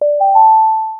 Deep, warm, intimate tone with subtle low pulse and soft airy chime. Clean, minimal, futuristic, unique, catchy, premium message alert.” 0:01 K-pop pop akustik 0:47 Generate a 10-second rhythmic wheel spin sound with musical elements.
notification-sound-for-tr-rwvgq7ee.wav